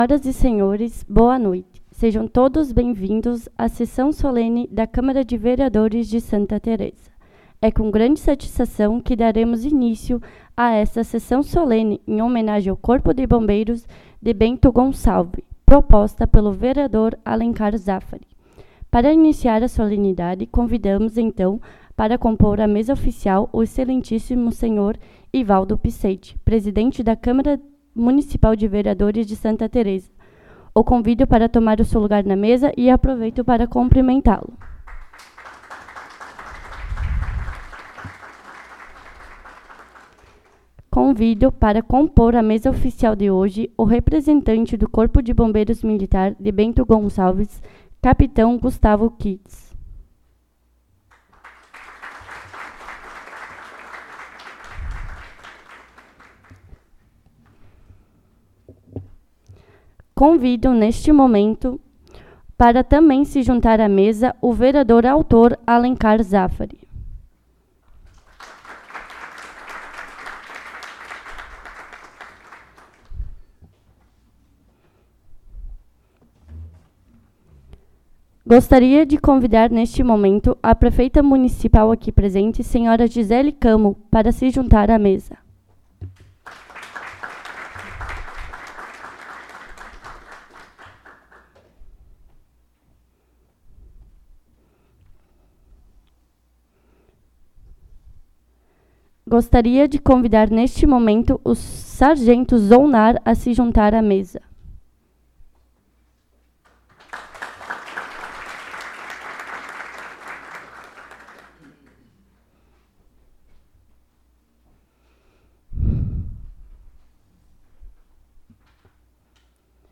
1° Sessão Solene de 2024
Sessão Solene em homenagem ao Corpo de Bombeiros Militar de Bento Gonçalves.
1° Sessão Solene de 2024 Data: 3 de abril de 2024 Horário: 19:30 Local: Plenário Pedro Parenti Sessão Solene em homenagem ao Corpo de Bombeiros Militar de Bento Gonçalves.